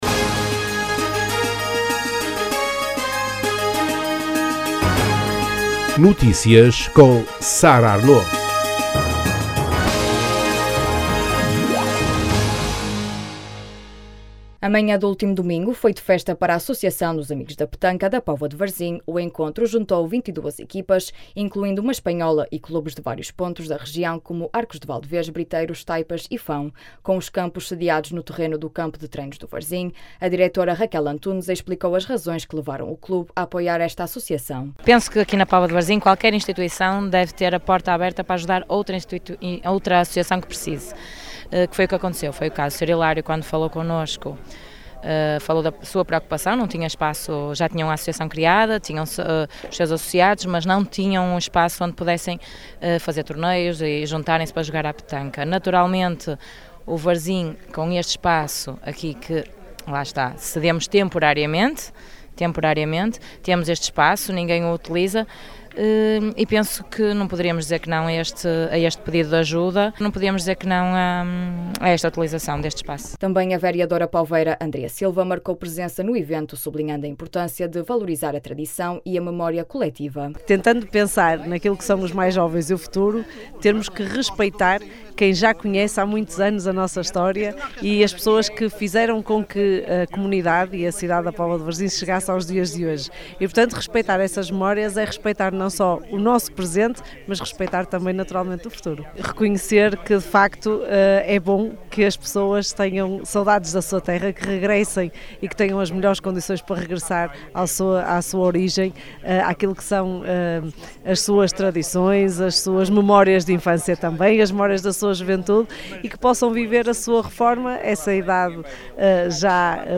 Também a vereadora poveira Andrea Silva marcou presença no evento, sublinhando a importância de valorizar a tradição e a memória coletiva. Ricardo Silva, presidente da União de Freguesias da Póvoa de Varzim, Beiriz e Argivai, destacou que a petanca tem vindo a crescer e a trazer cada vez mais praticantes à cidade.